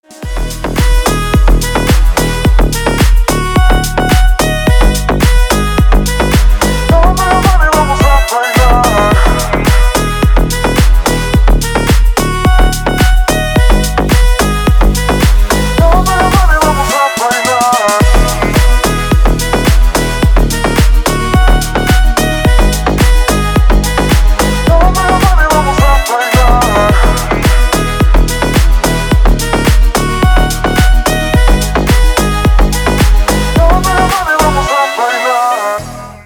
Рингтоны Deep House ремейков
• Песня: Рингтон, нарезка